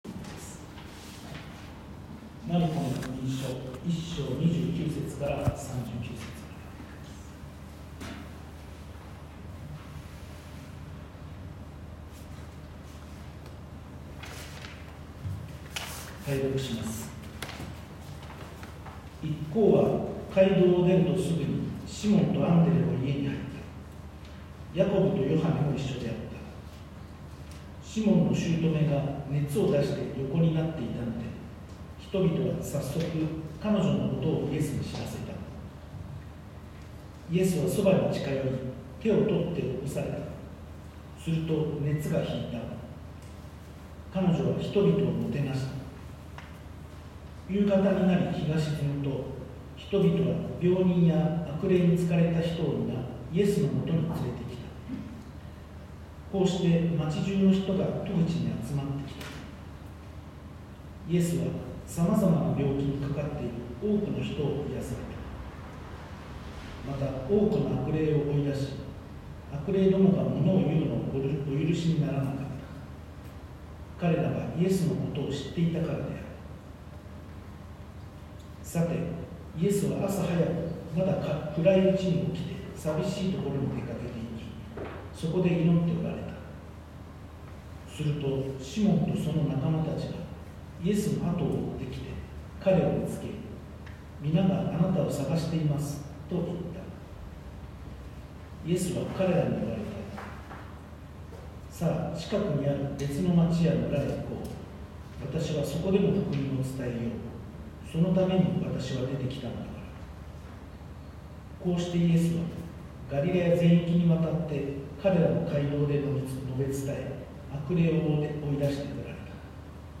丘の上礼拝説教